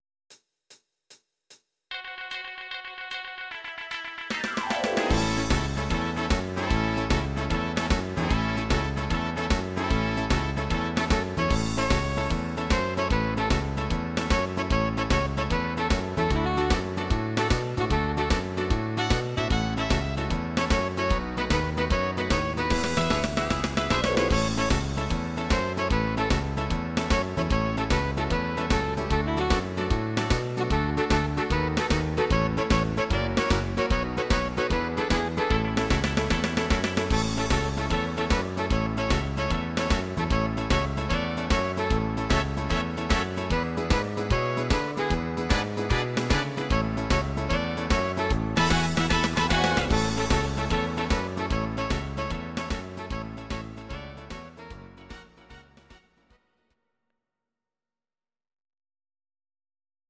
Audio Recordings based on Midi-files
Pop, Oldies, 1960s